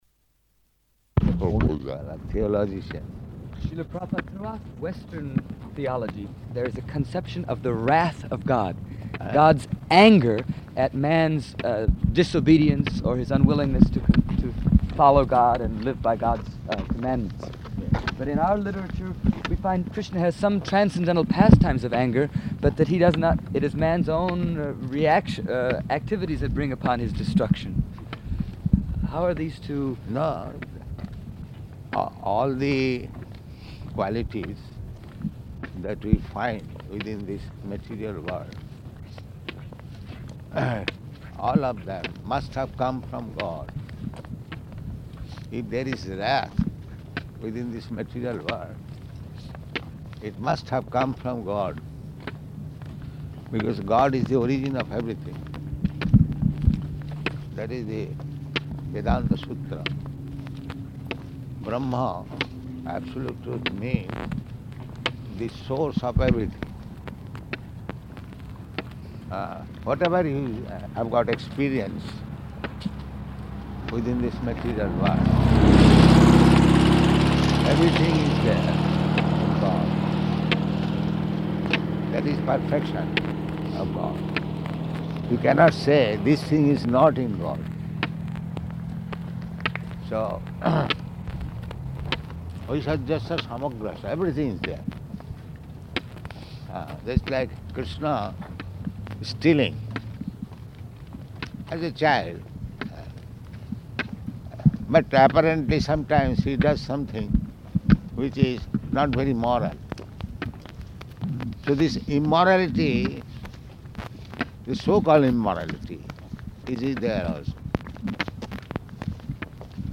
Type: Walk
Location: Los Angeles